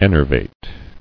[en·er·vate]